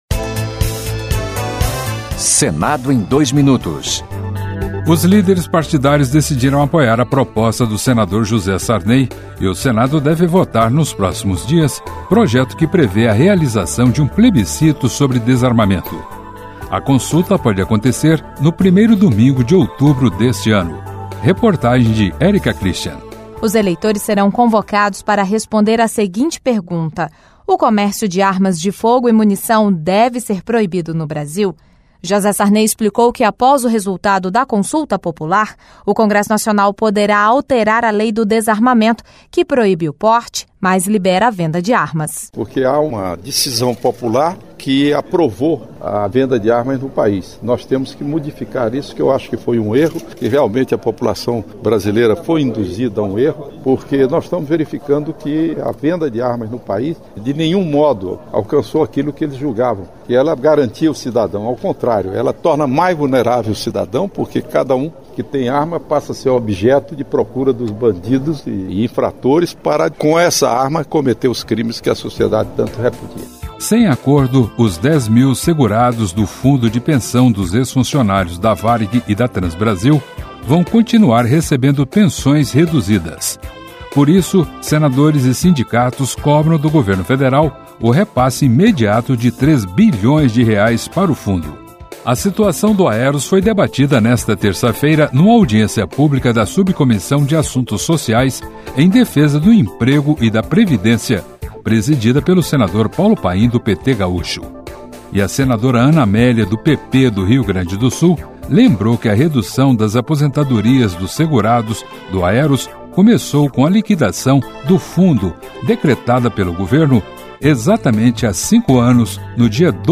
Um resumo das principais notícias do Senado